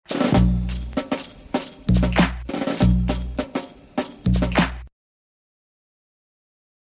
描述：流浪英雄的节拍，行进的节拍风格
声道立体声